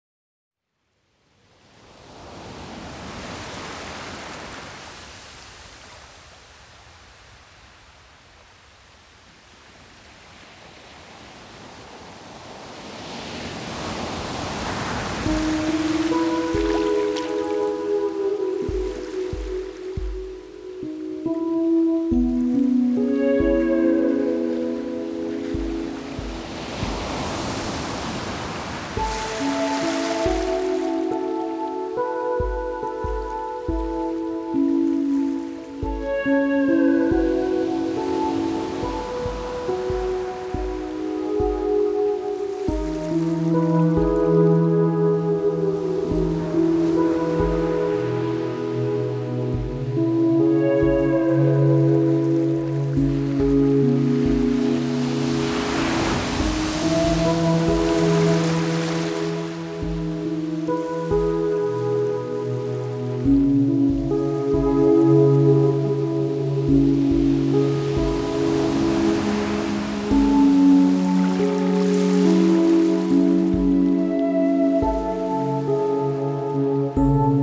Звуки природы [10]
Звуки моря
zvuki_morja.mp3